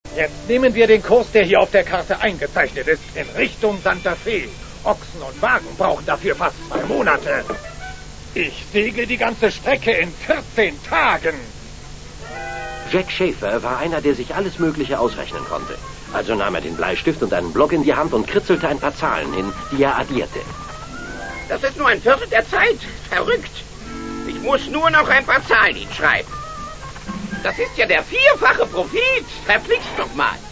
Berlin 80er Jahre:
Gesucht ist die Stimme von Windwagon Smith ganz am Anfang und von Jack Schaefer. Erzähler ist Lutz Riedel.